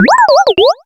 Catégorie:Cri Pokémon (Soleil et Lune) Catégorie:Cri de Croquine